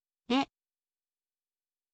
ออกเสียง: re, เระ